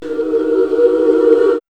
2605L CHOIR.wav